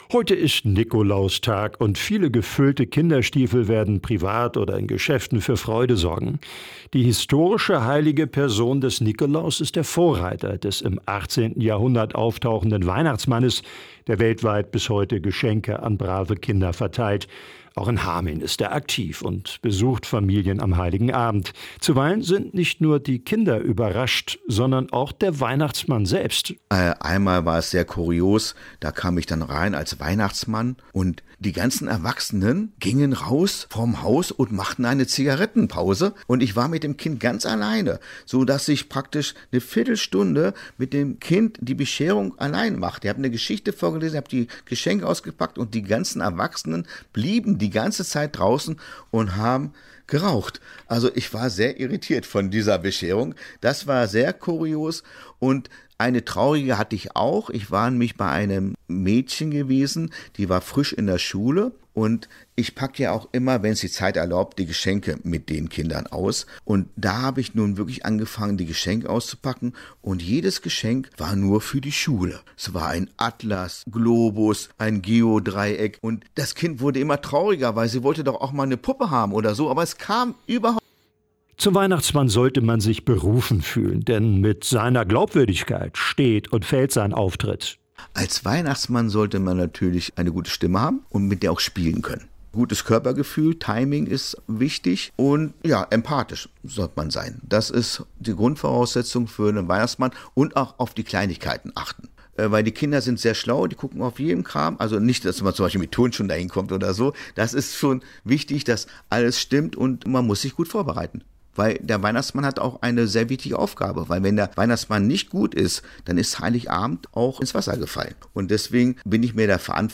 Hameln-Pyrmont: Der Weihnachtsmann im Interview – radio aktiv
hameln-pyrmont-der-weihnachtsmann-im-interview.mp3